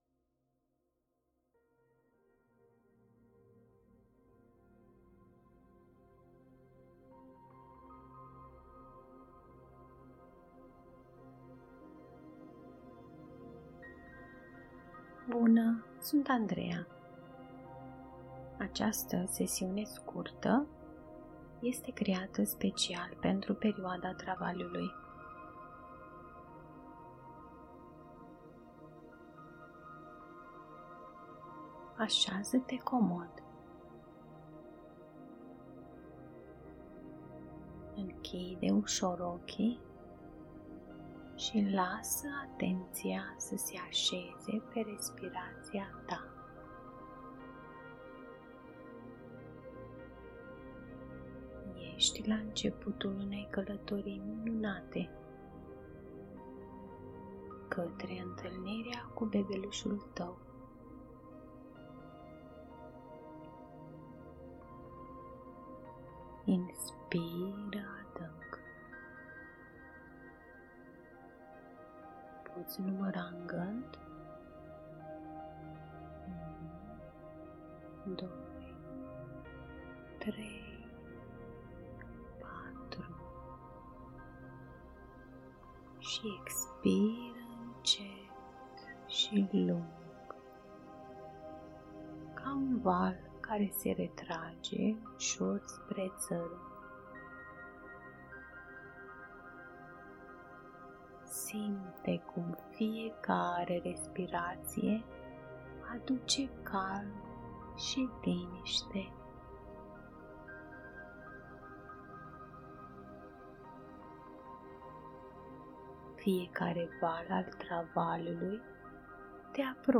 O scurtă meditație ghidată concepută pentru faza latentă a travaliului, când corpul tău începe să se pregătească pentru naștere. Te invit să te relaxezi profund, să respiri conștient și să te conectezi cu încrederea naturală pe care o ai în tine și în procesul nașterii.